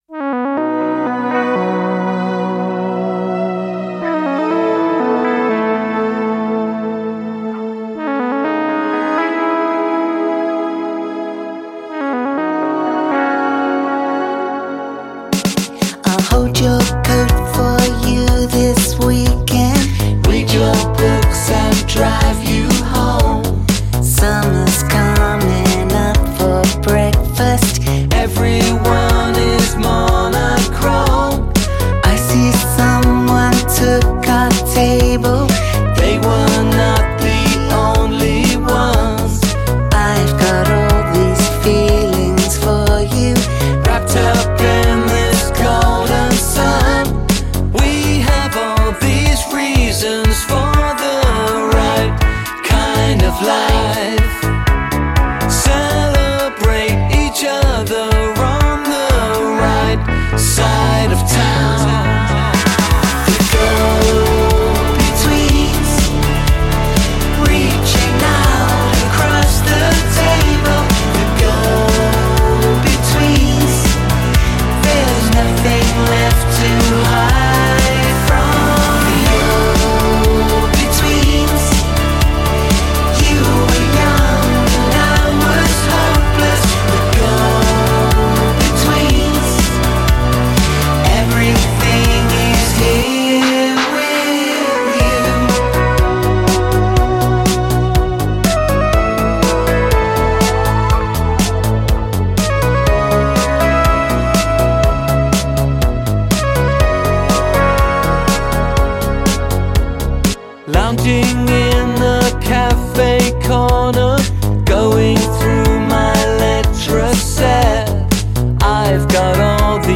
поп музыка
поп-трио